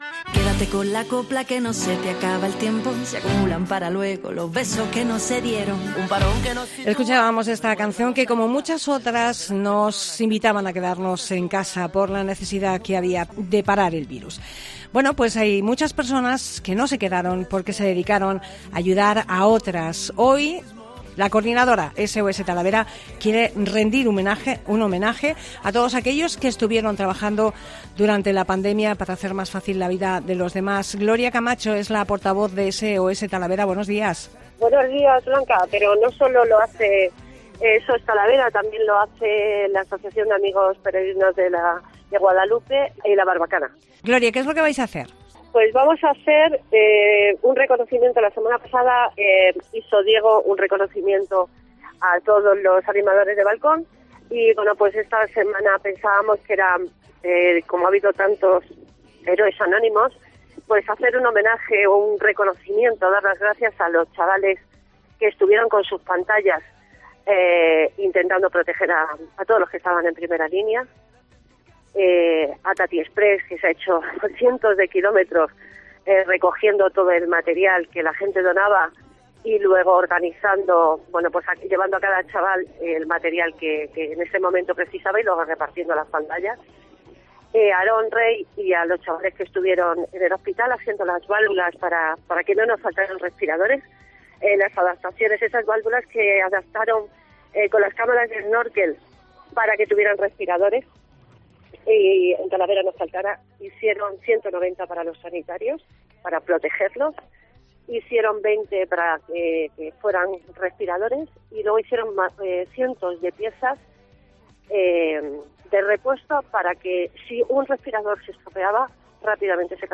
Talavera de la Reina Entrevista